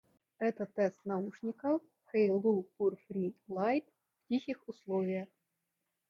Двойной микрофон с шумоподавлением cVc во время звонков работает хорошо.
В тихих условиях:
haylou-purfree-lite-v-tihih-uslovijah.mp3